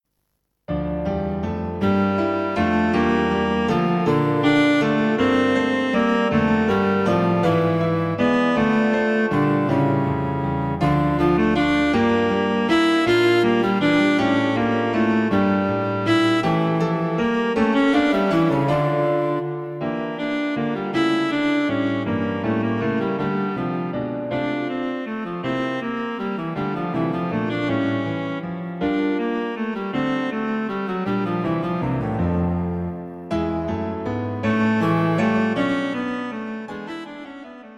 Cello and Piano An uplifting piece in uptempo 3/4 time.